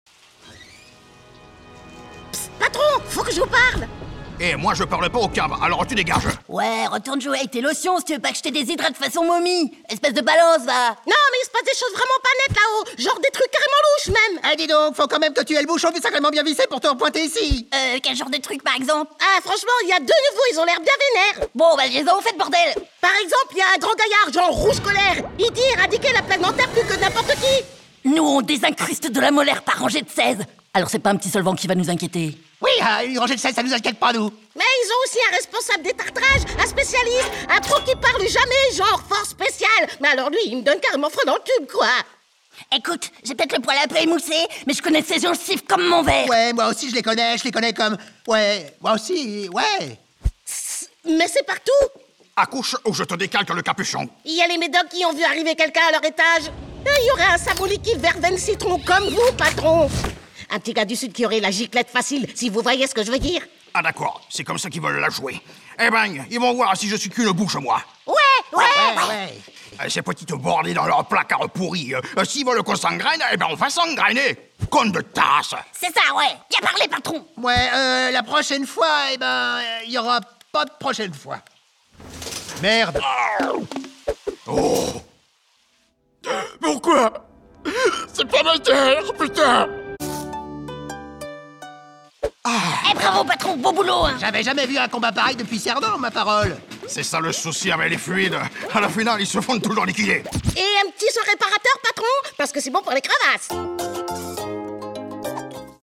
VOIX OFF – SERIE d’ANIMATION « Objectivement » (le patron et Rambo)